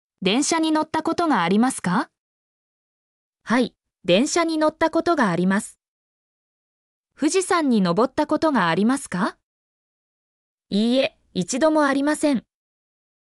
mp3-output-ttsfreedotcom-50_MCiCPMPZ.mp3